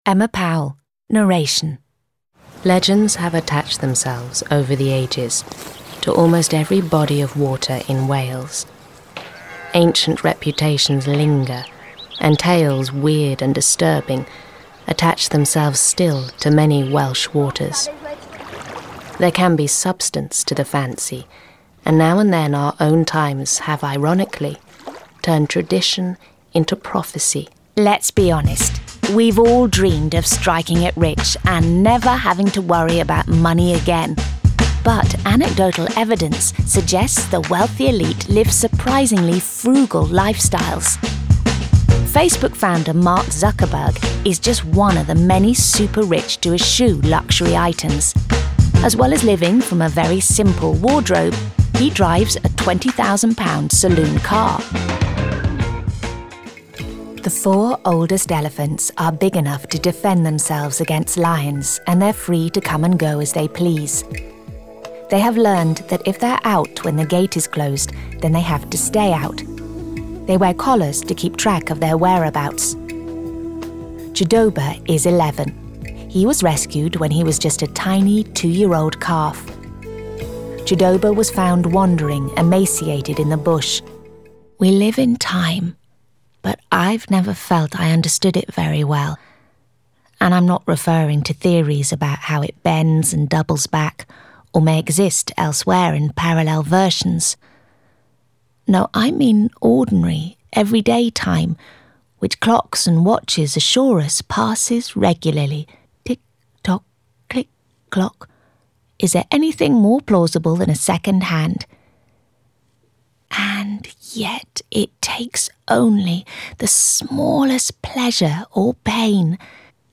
I have a naturally husky, sexy mid tone.
Narration